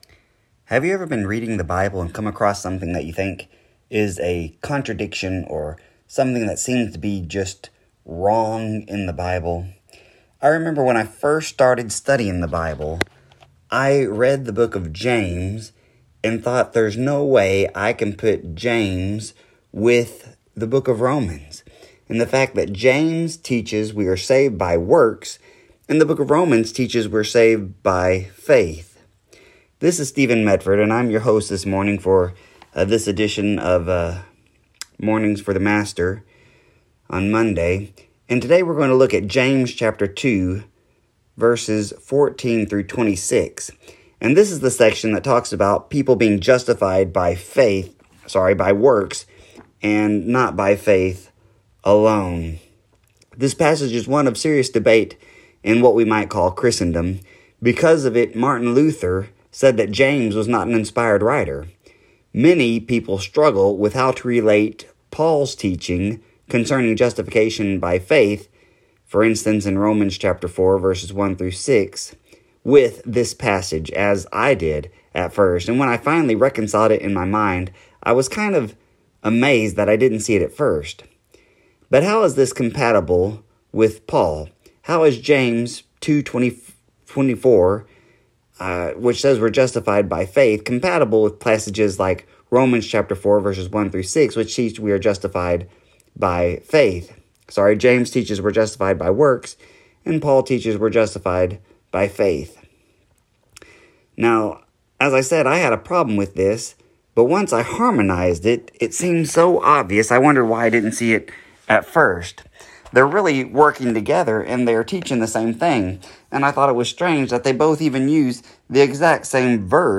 Program Info: Live program from the TGRN studio in Mount Vernon, TXClick here for current program schedule.